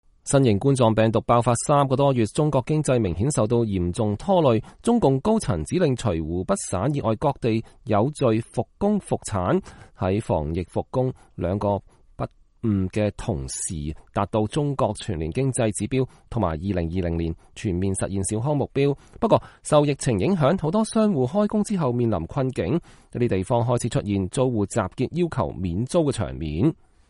不過，受疫情影響，許多商戶開工後面臨囧境，一些地方開始出現租戶集結要求免租的場面。美國之音記者近日在北京就企業房租、效益、員工健康和權益等問題隨機採訪了一些店主和僱員。